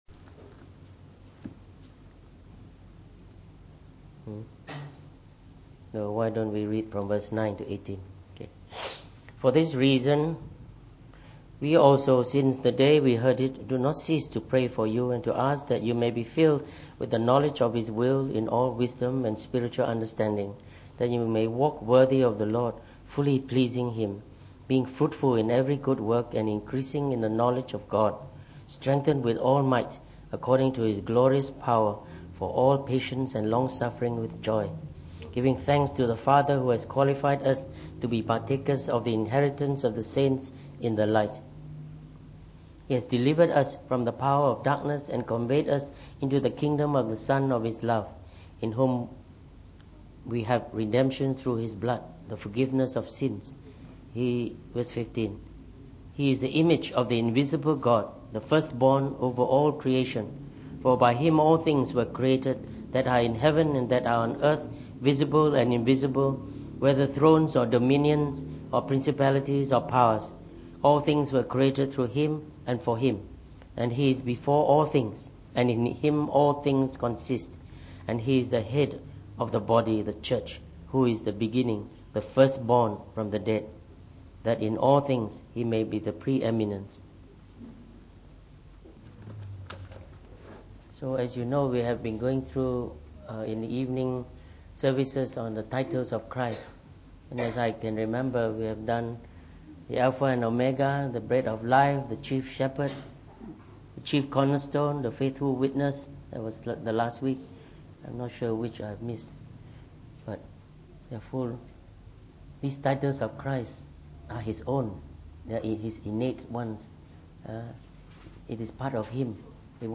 From our series on the Titles of Jesus Christ delivered in the Evening Service.